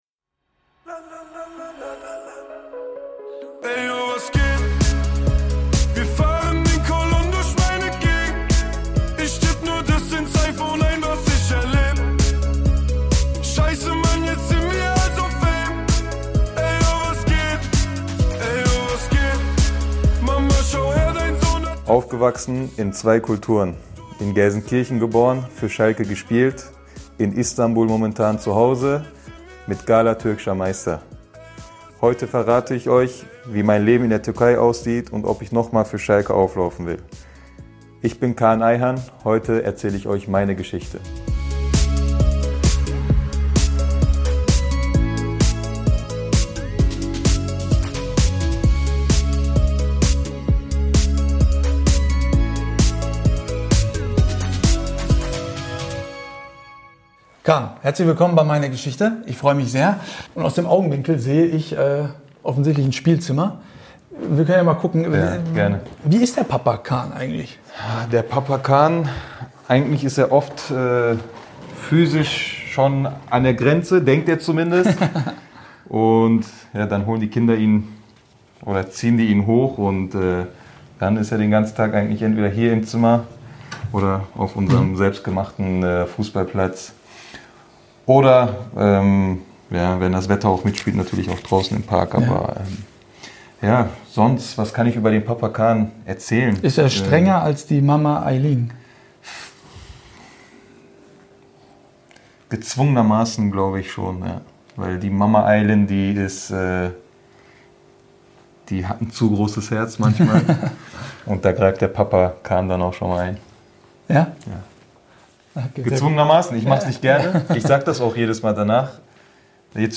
Dabei stehen persönliche Geschichten abseits des Spielfelds im Vordergrund. Wir blicken im Gespräch hinter die Kulissen der Sportler oder der Sport-Persönlichkeiten.